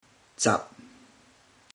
Click each Romanised Teochew word to listen to how the Teochew word is pronounced.
zab1